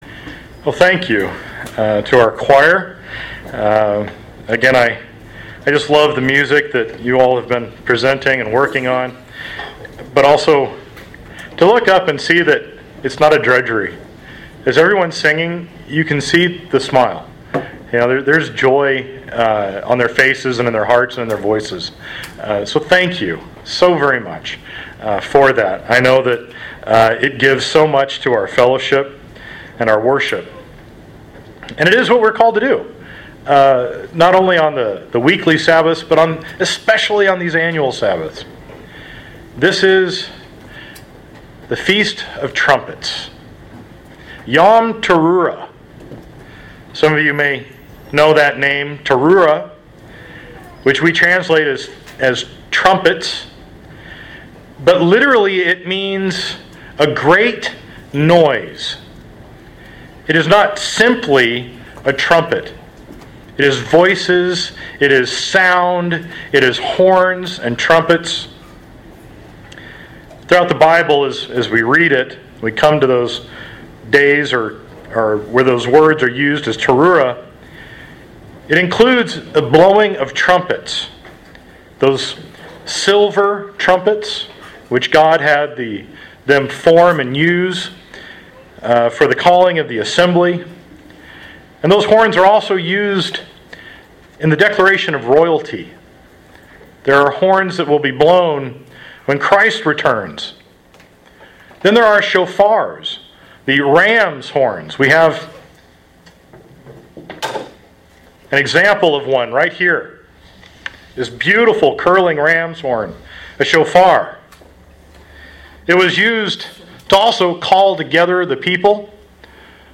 Sermons
Given in Sioux Falls, SD Watertown, SD